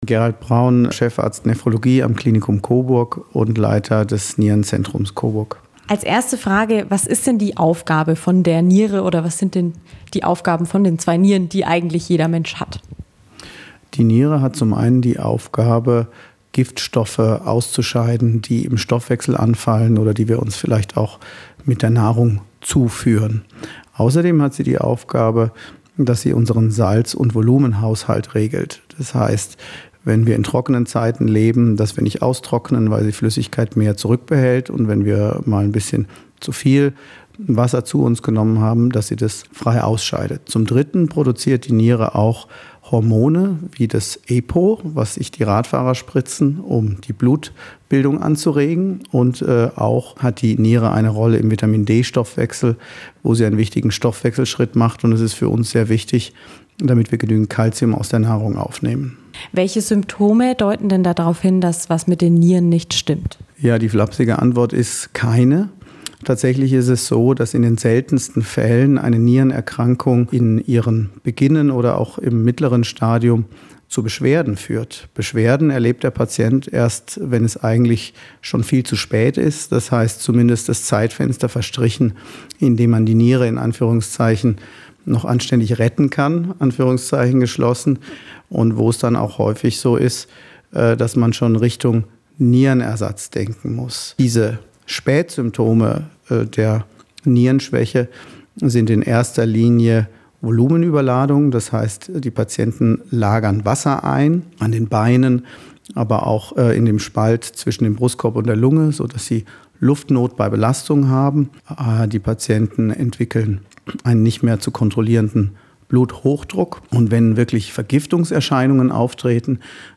Unser Lokalsender Radio Eins im Experteninterview: